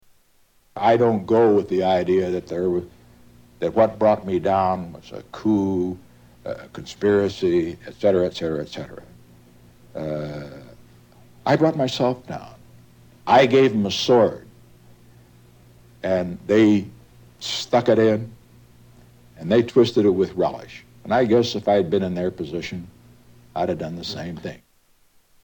Category: History   Right: Personal
Tags: Historical Frost Nixon Interview Audio David Frost Interviews Richard Nixon Political